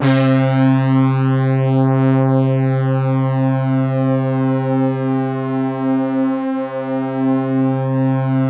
BRASS1 MAT12.wav